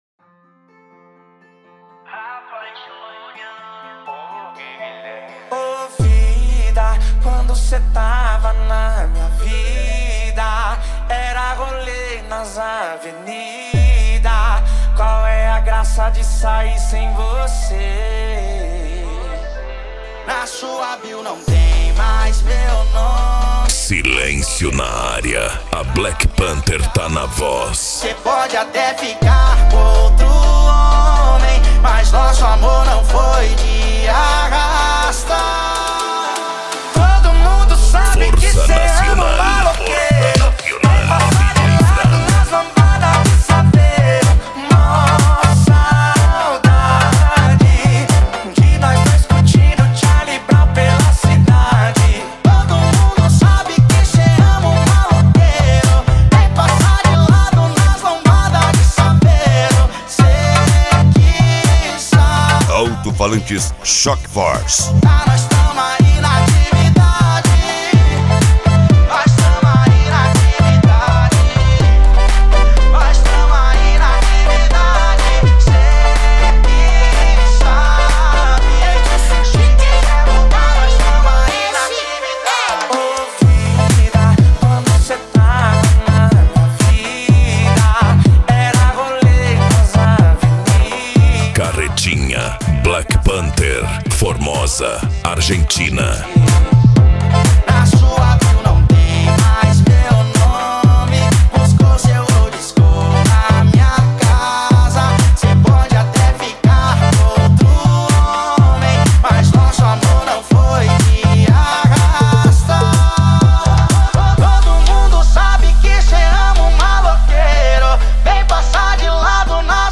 Bass
Funk
Psy Trance
Remix